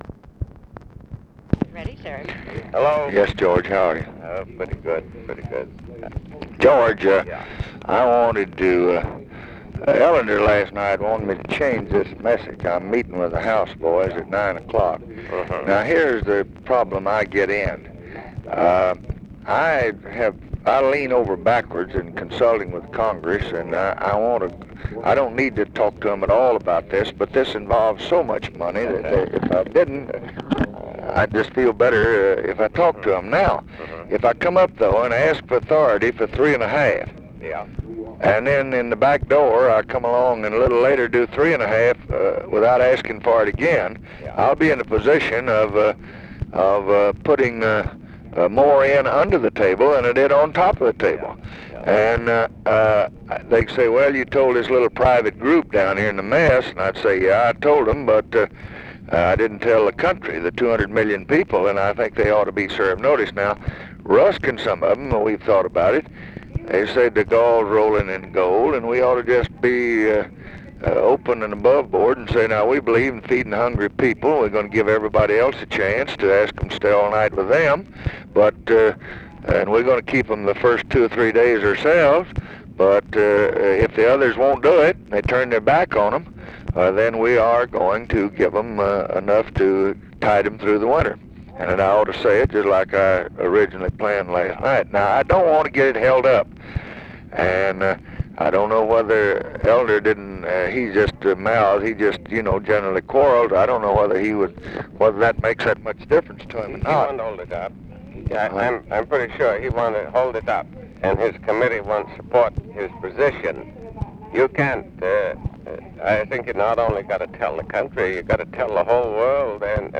Conversation with GEORGE AIKEN, March 30, 1966
Secret White House Tapes